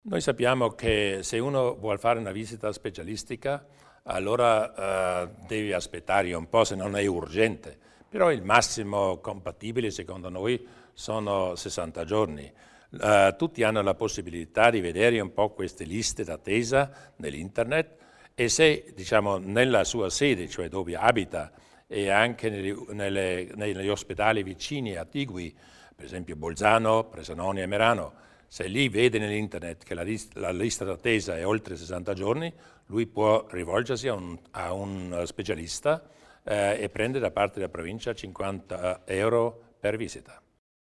Il Presidente Durnwalder illustra le novità per coloro che usufruiscono delle visite specialistiche